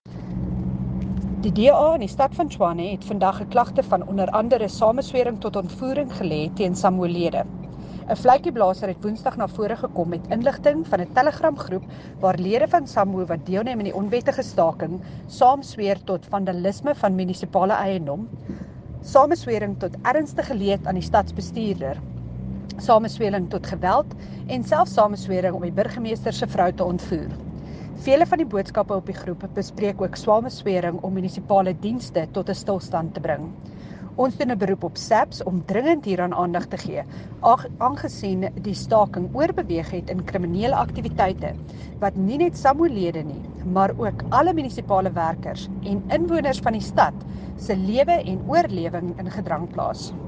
Issued by Cllr Kwena Moloto – DA Tshwane Caucus Spokesperson
here, Afrikaans soundbite